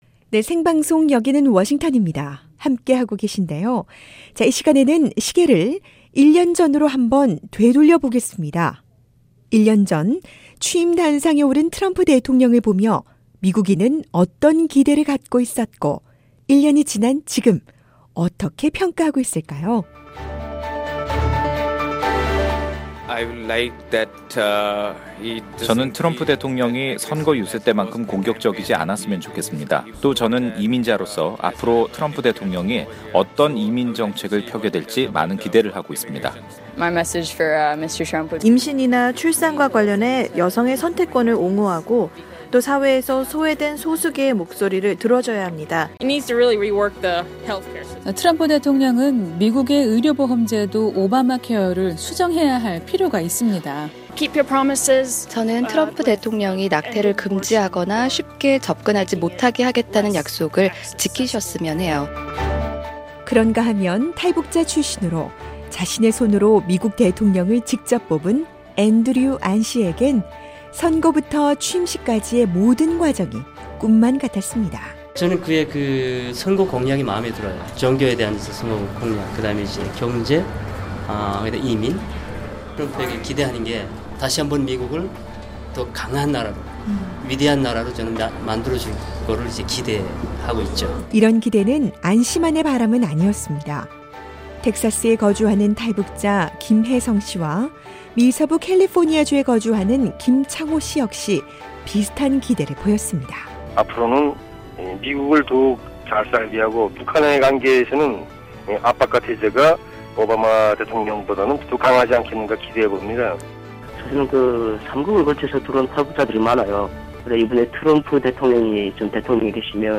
1년 전 취임 단상에 오른 트럼프 대통령을 보며 미국인은 어떤 기대를 갖고 있었고, 1년이 지난 지금은 어떻게 평가하고 있을까요? 오늘은 트럼프 대통령 취임 1주년 특집 방송으로 미국 국민들의 평가와 기대를 들어봅니다.